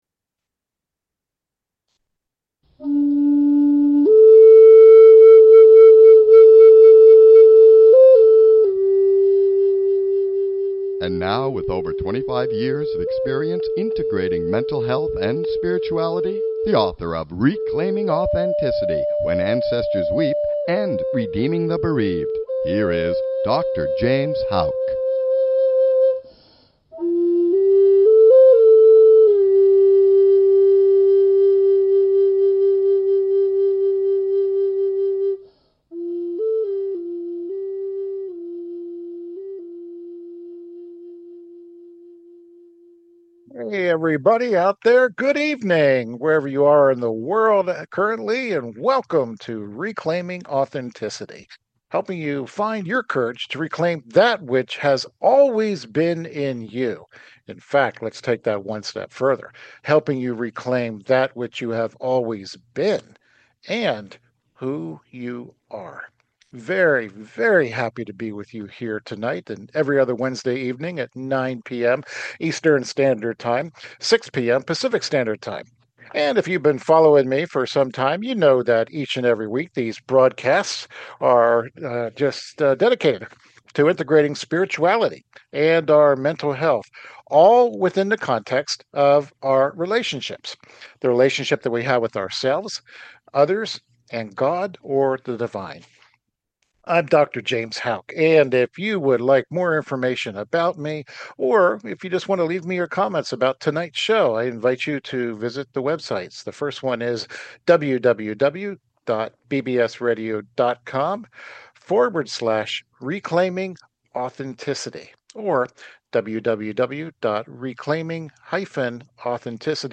Talk Show Episode, Audio Podcast, Reclaiming Authenticity and What About Bob?